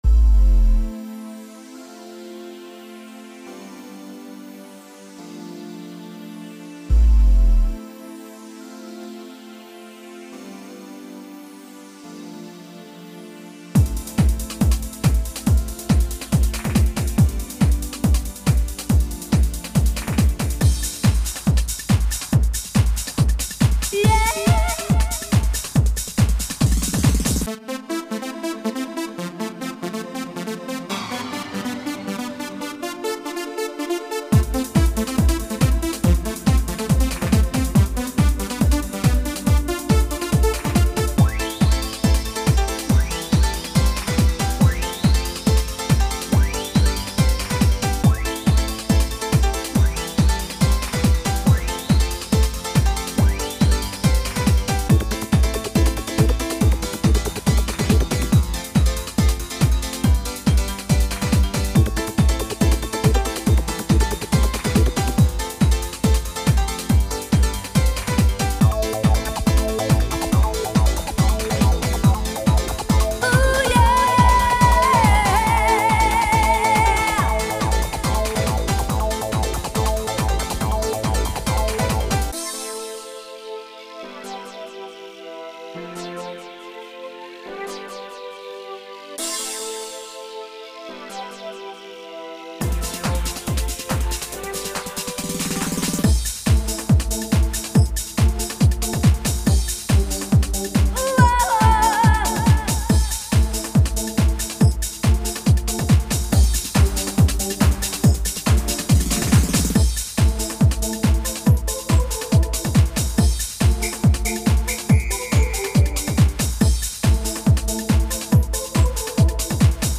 :) I'll also have a link to my own original songs and clips of me singing For now check out this song I just created with a program called "EJay": My Dance-Techno Mix